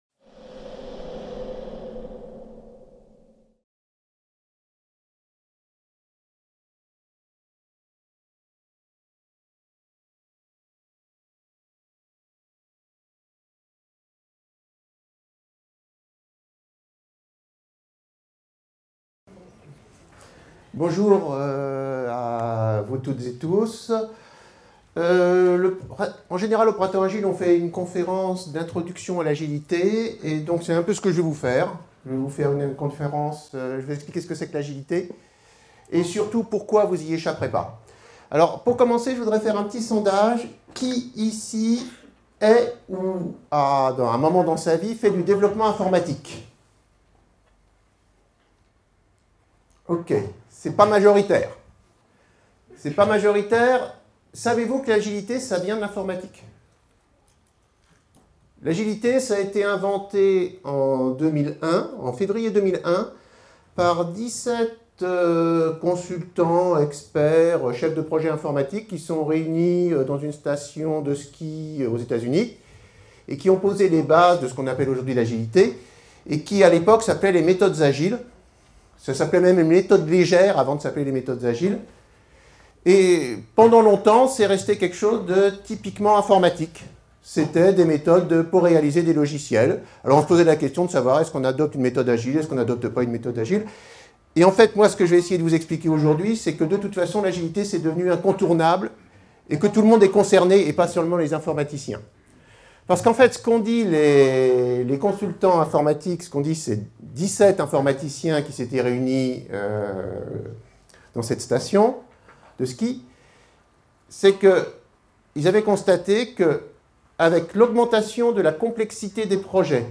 La conférence